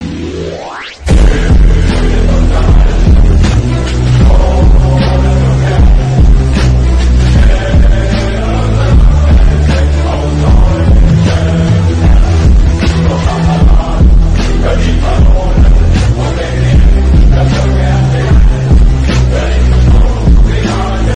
Presný názov nepoviem, avšak hudba mi znie ako nejaká varianta na Fler - NDW, nahrávka nie je dobrá, nedalo sa mi rozpoznať spev, takže presne neviem, ale ak ho viete rozoznať, skúste text naťukať čo možno najpresnejšie do googlu, možno niečo rozumné vypadne.